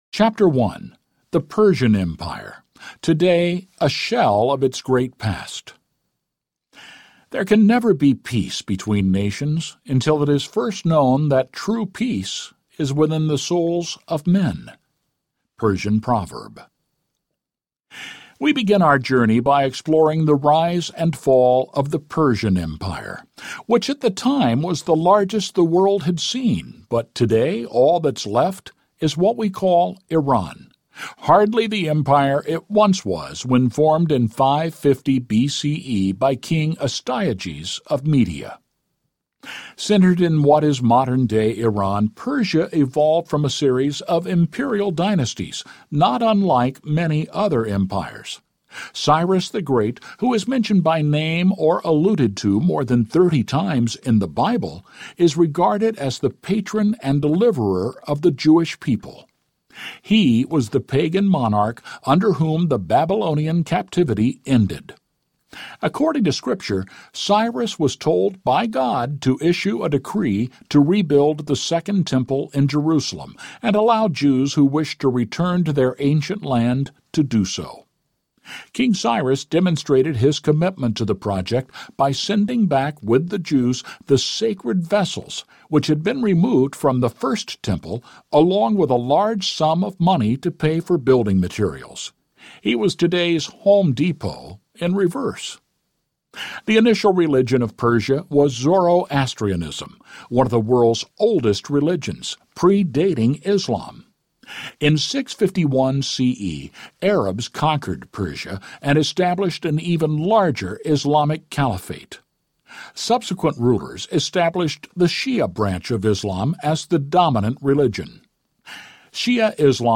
America’s Expiration Date Audiobook
Narrator
6.0 Hrs. – Unabridged